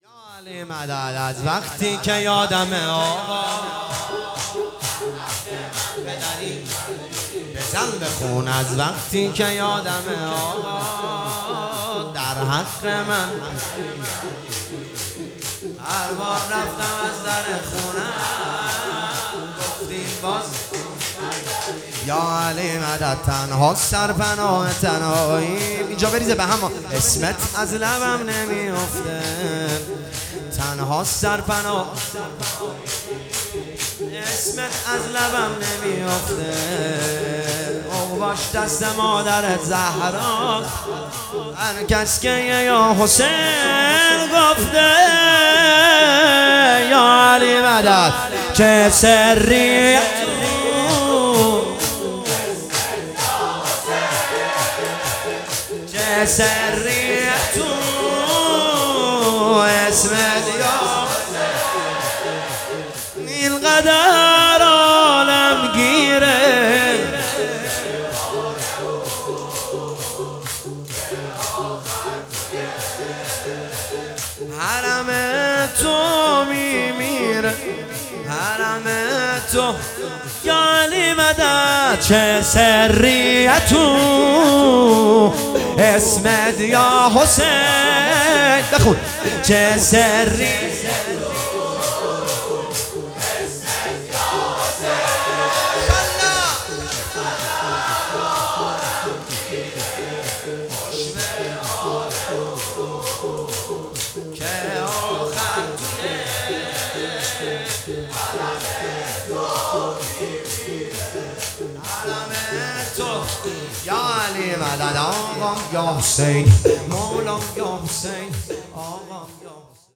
مداحی شور امام حسین (ع)
جلسه هفتگی اردیبهشت 1404